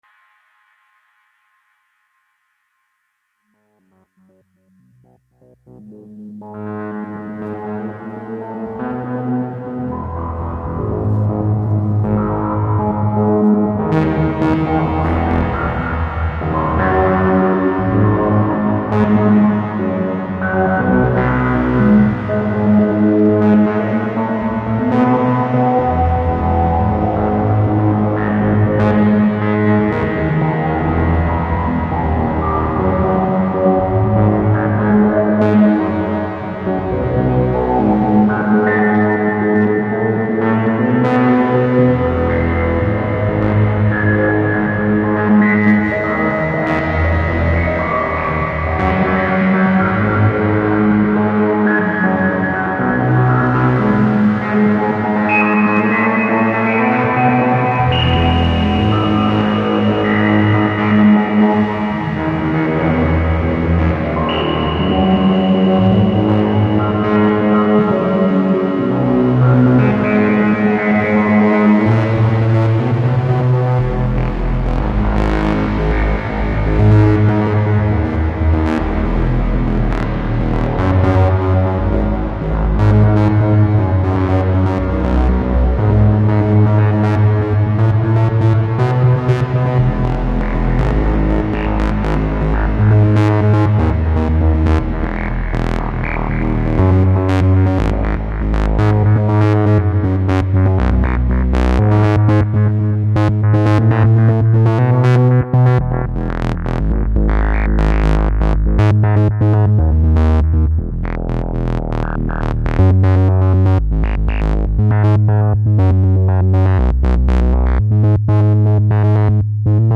Morning test with Shakmat Griffin’s Claw as a melodic pattern generator for Strega and 0Coast through PamPro’s quantizer.
Addac VC Transitions for switching states from Strega/0Coast/DFAM.